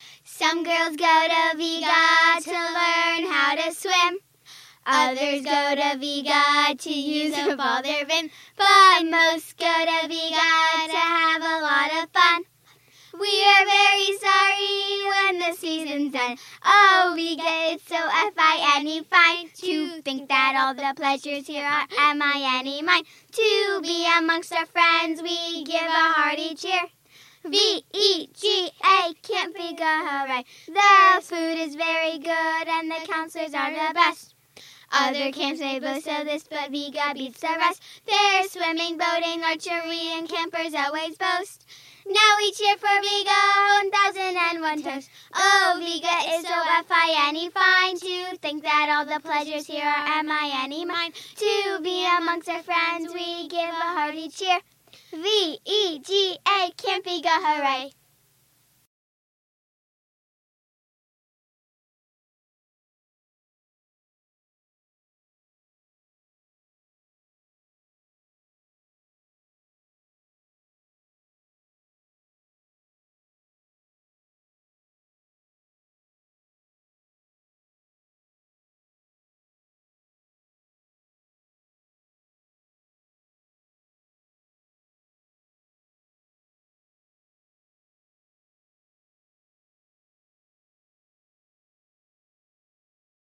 This page is dedicated to sharing songs sung at camp, and in particular at Sunset Circle, Vega’s weekly all-camp campfire.
Currently, only all-camp songs are posted on this page.